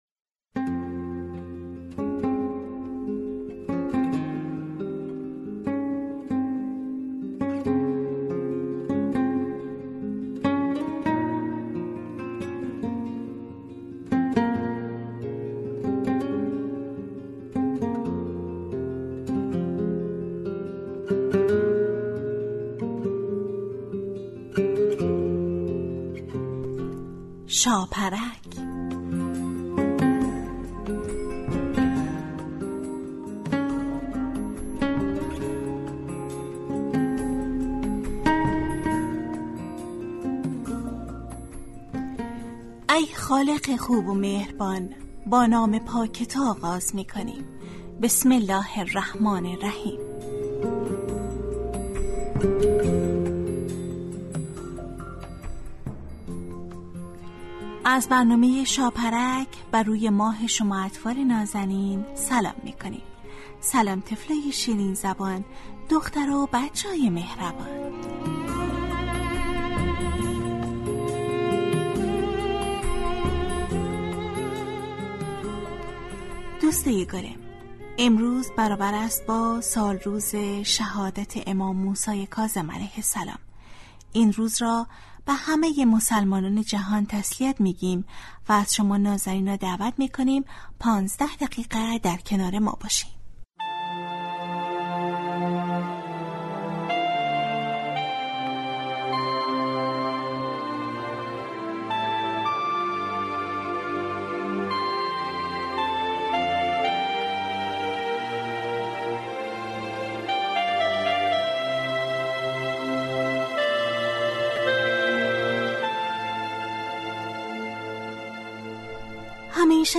برنامه ای ترکیبی نمایشی است که برای کودکان تهیه و آماده می‌شود. این برنامه هر روز به مدت 15 دقیقه با یک موضوع مناسب کودکان در ساعت 8:15 صبح به وقت افغانستان از رادیو دری پخش می گردد.